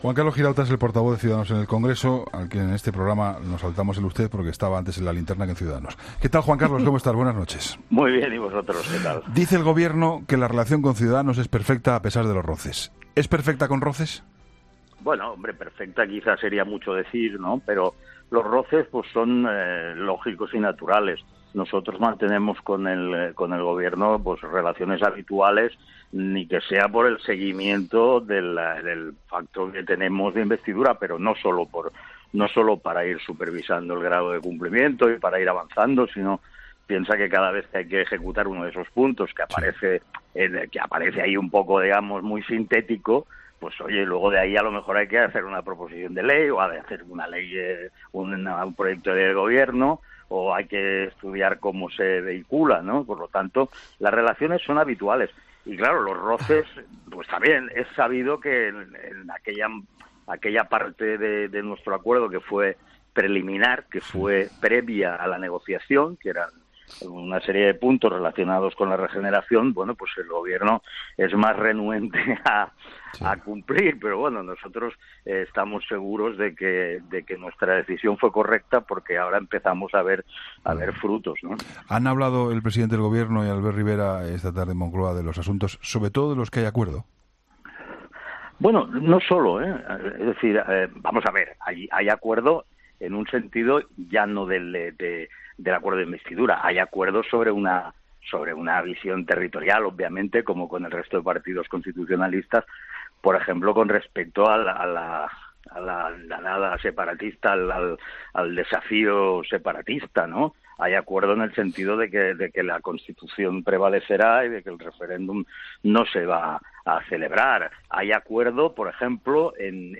Entrevista a Juan Carlos Girauta